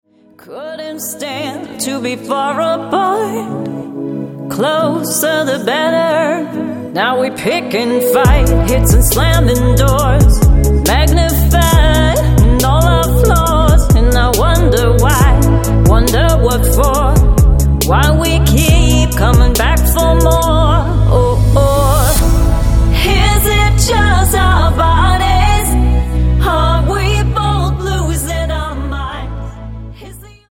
Tonart:A Multifile (kein Sofortdownload.
Die besten Playbacks Instrumentals und Karaoke Versionen .